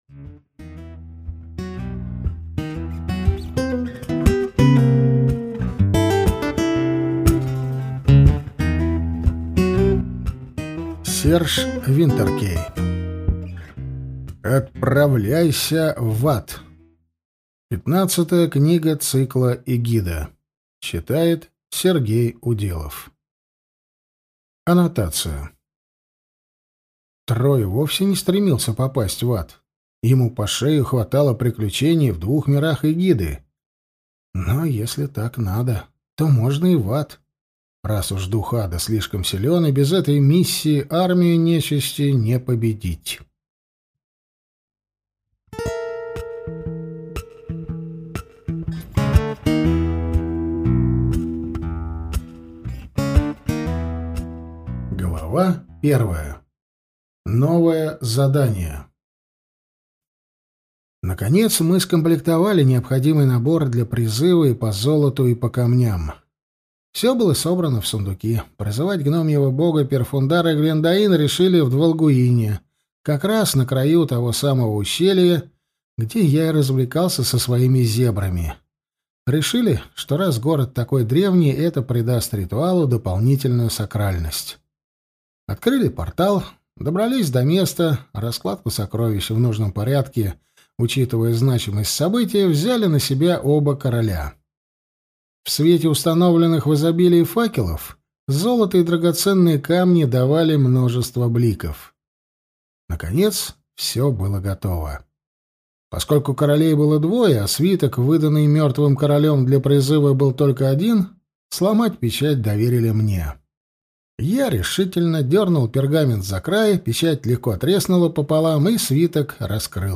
Аудиокнига Отправляйся в ад!